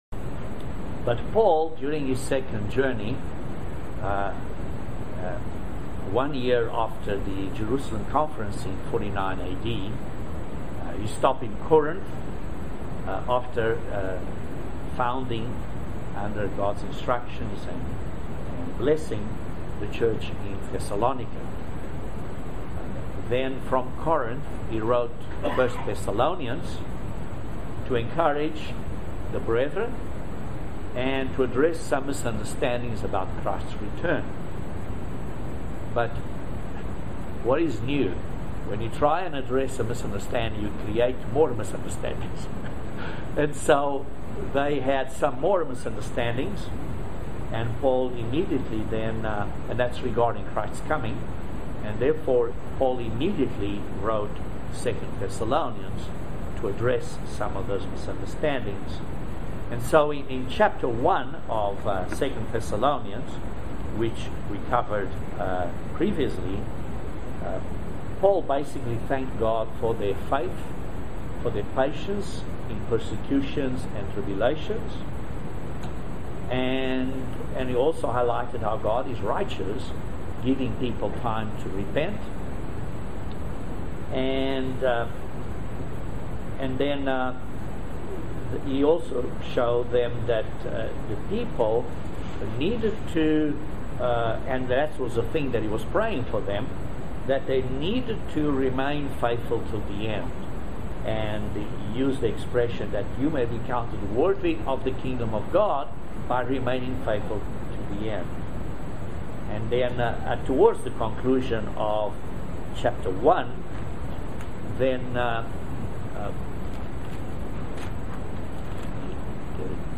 Join us for this excellent video Sermon on the study of 2nd Thessalonians chapter 2.